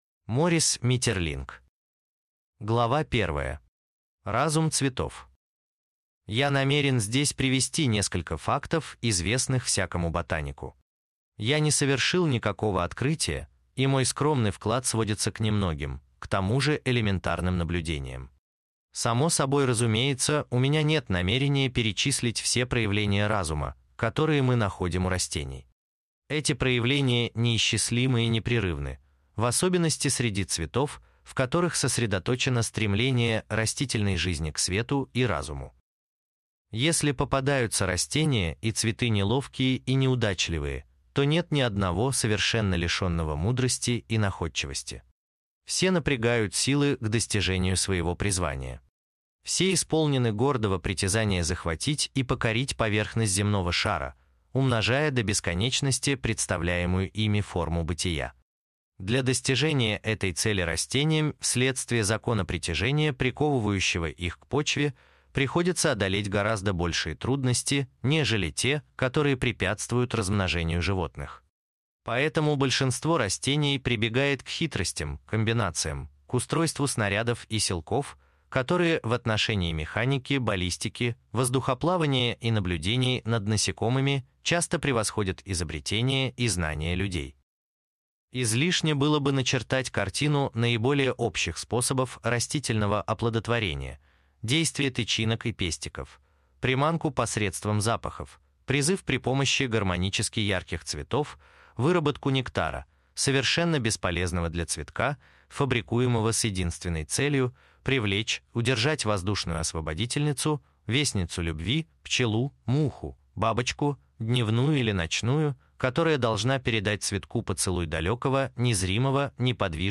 Аудиокнига Разум цветов | Библиотека аудиокниг